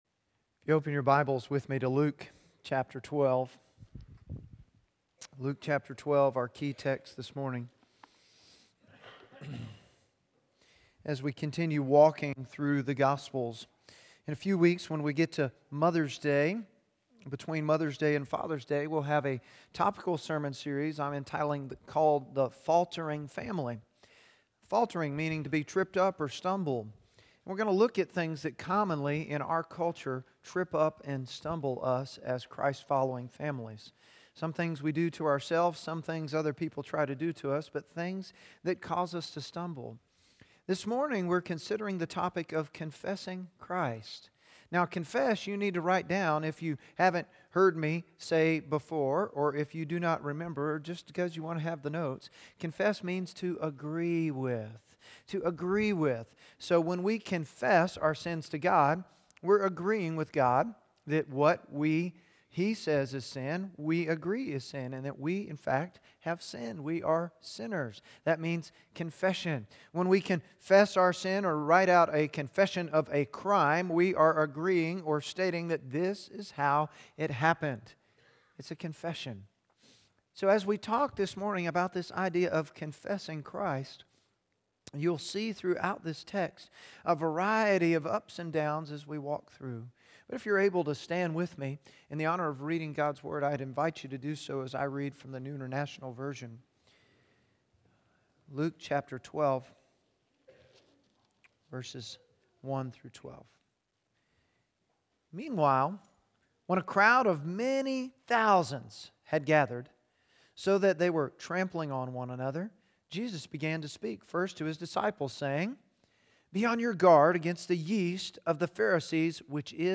Luke 12:1-12 Sermon notes on YouVersion Following Jesus: Confessing Christ